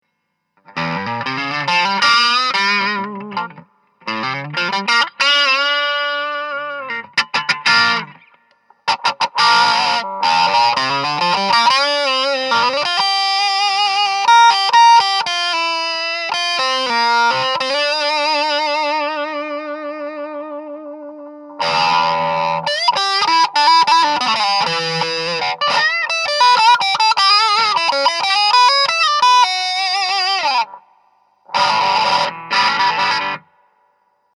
ギターでも弾いてお茶を濁すとします。
ブレッドボードで組んだワウ回路でRinを可変にして小さく設定
半止めワウエフェクターとでも言いますか？
それなりにブーストしてますね
wah.mp3